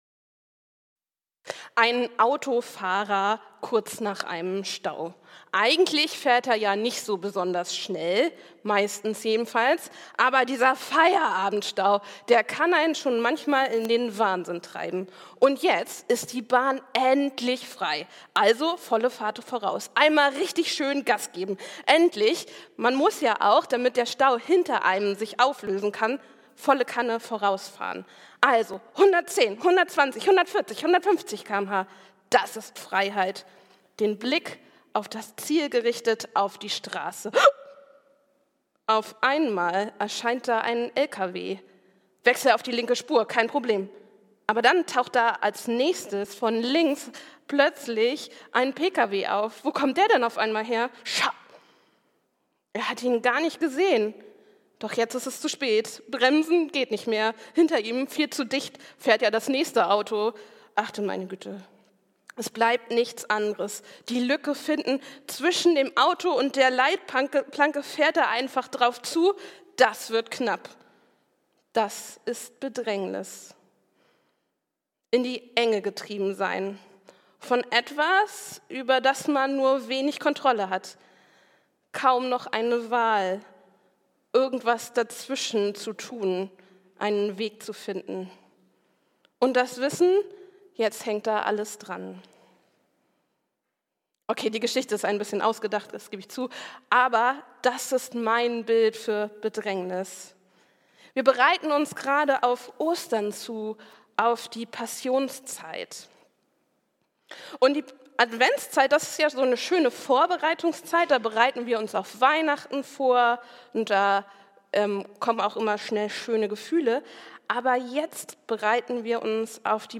Bedrängnis und Gnade ~ Christuskirche Uetersen Predigt-Podcast Podcast